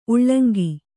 ♪ uḷḷaŋgi